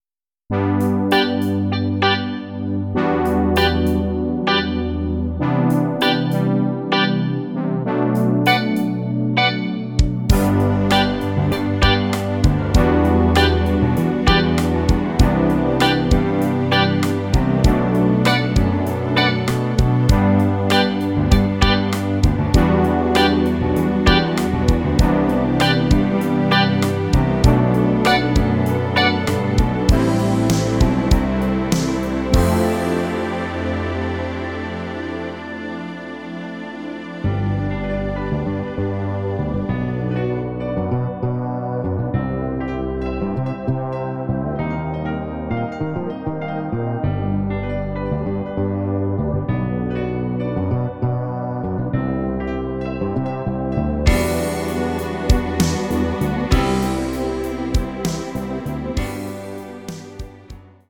Kultballade der 80er